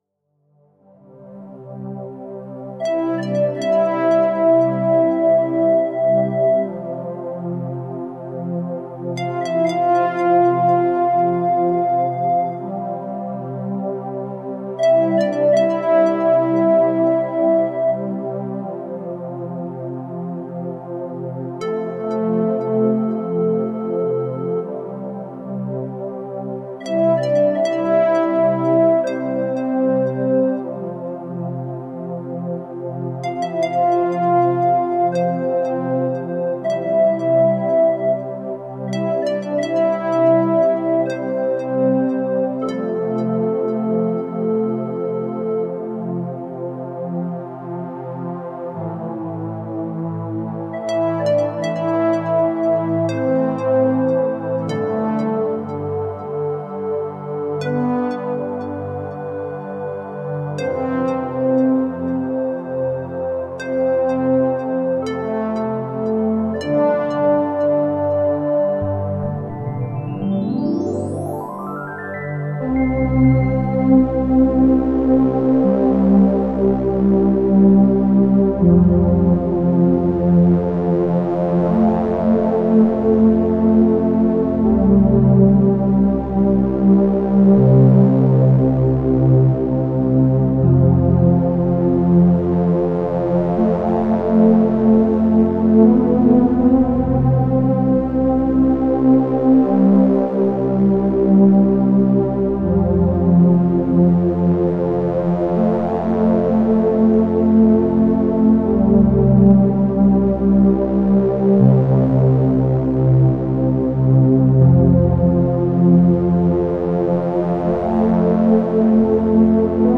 muzyki g��boko relaksacyjnej